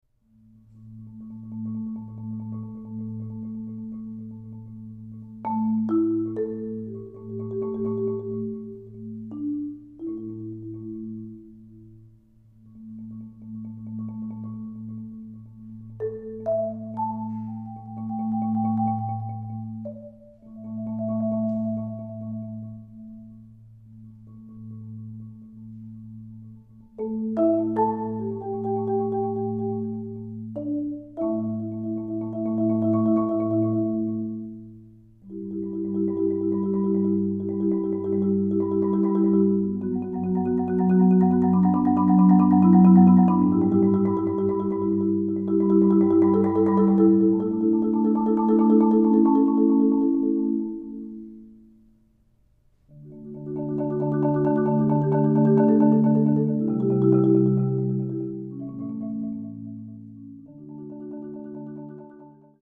Genre: Marimba (4-mallet)